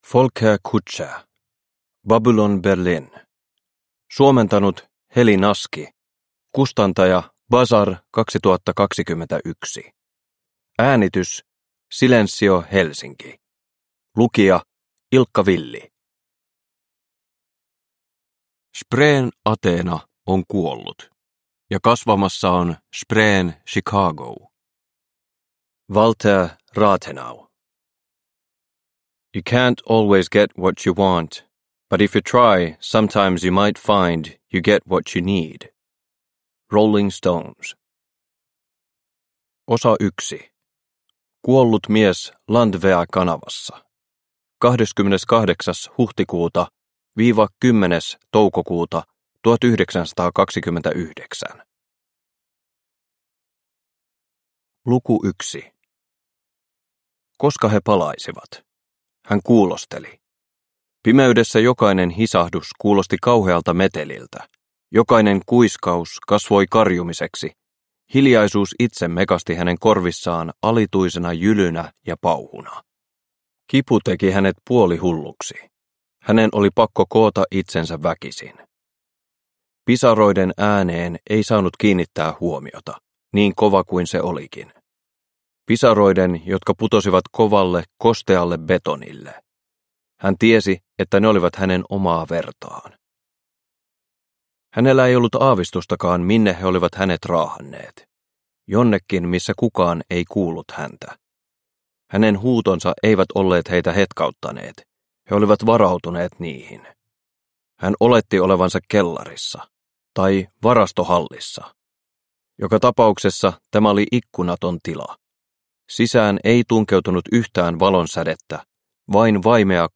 Babylon Berlin – Ljudbok – Laddas ner